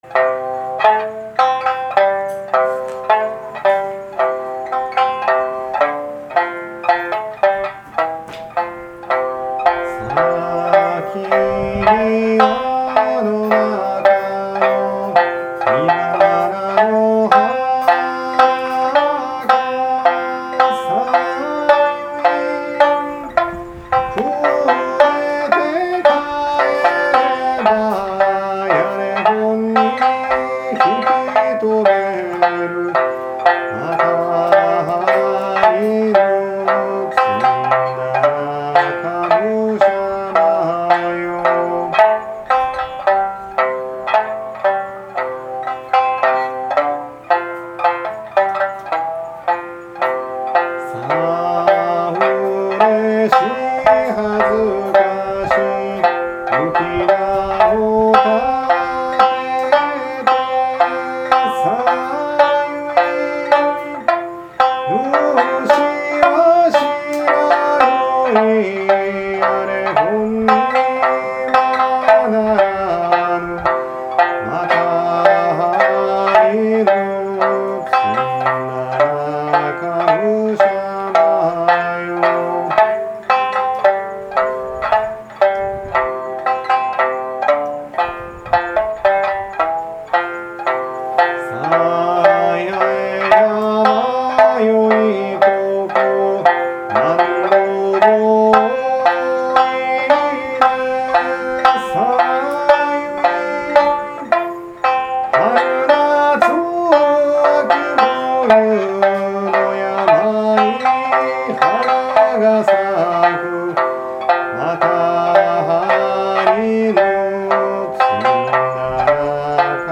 ・　石垣島の民謡「安里屋（あさどや）ユンタ」をアップしました。
◎　お兄さんが唄う「安里屋ユンタ」
「やいま」が「八重山」のことだと初めて知ったり、お兄さんが三味線をひきながら唄う「安里屋（あさどや）ユンタ」に聞きほれた後、滅多に来ないバスを待って宿に向った。